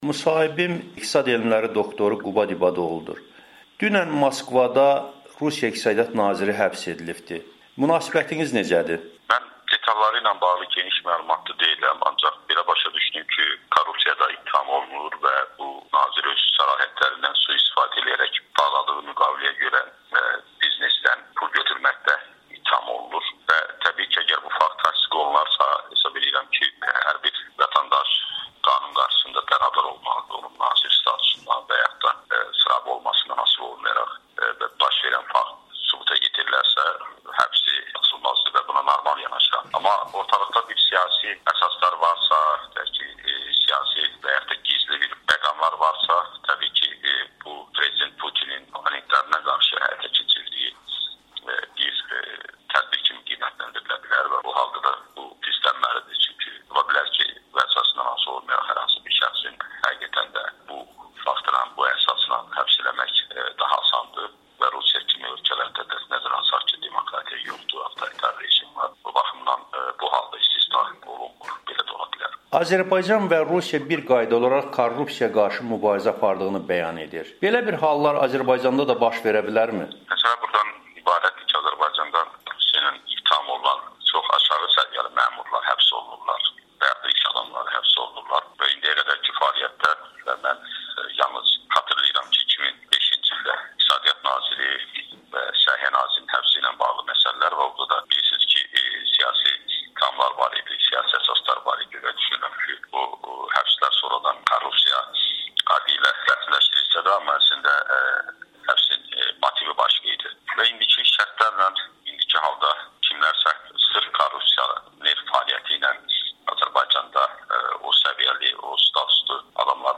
Müsahibələri təqdim edirik: